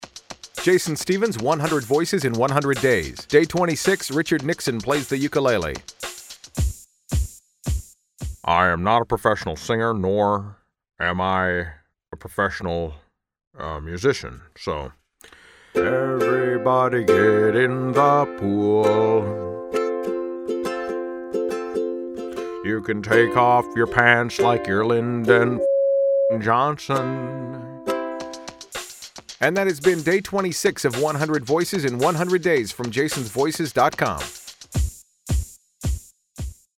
By breaking out my under-used Richard Nixon impression – and my appropriately under-used ukulele – for something extra political (and extra special).
Tags: 100 voices in 100 Days, Celebrity impersonation, Richard Nixon impression